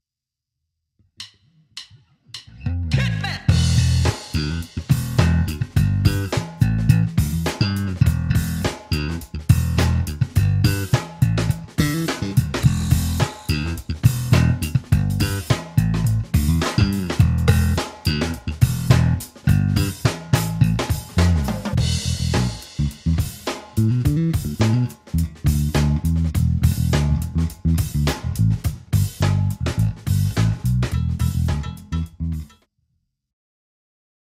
Slap Jazz Bass